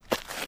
High Quality Footsteps
MISC Concrete, Foot Scrape 05.wav